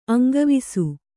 ♪ aŋgavisu